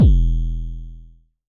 Index of /m8-backup/M8/Samples/Drums/LookIMadeAThing Kicks - Vermona Kick Lancet/Distortion Kicks/Dist Kick - Precise
Dist Kicks 04 - E1.wav